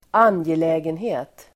Uttal: [²'an:jelä:genhe:t]